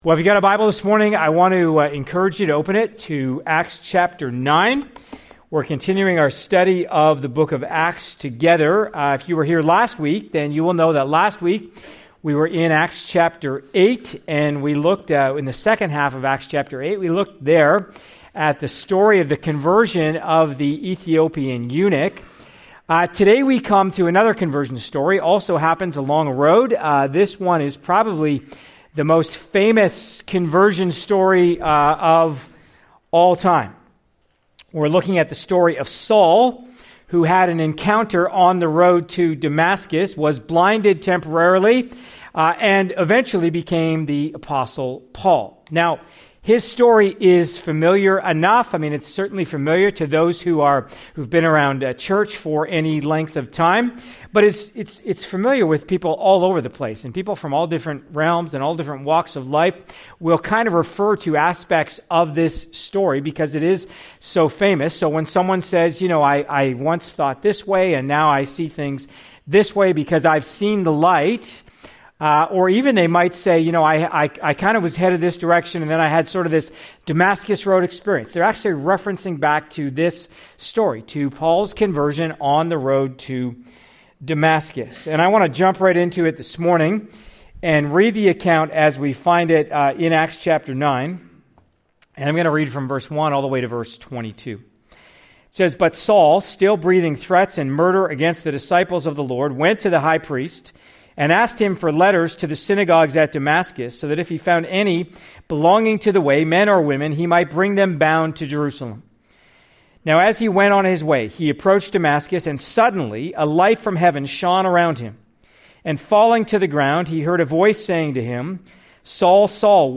Part of our series, ACTS: Mission & Message (click for more sermons in this series).